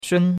shun1.mp3